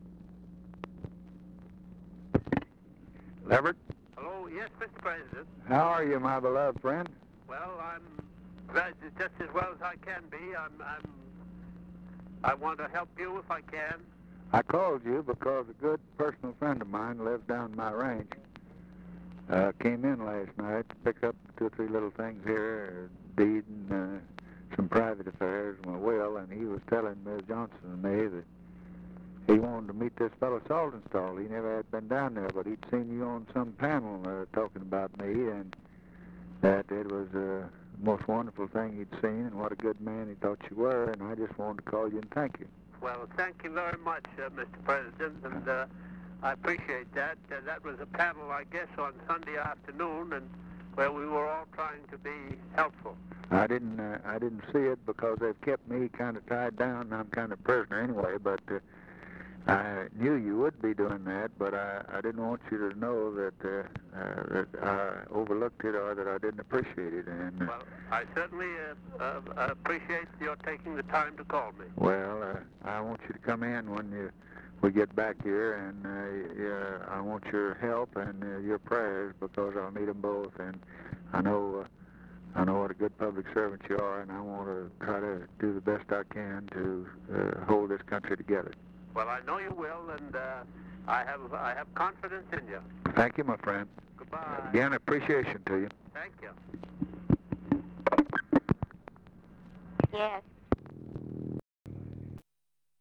Conversation with LEVERETT SALTONSTALL, November 29, 1963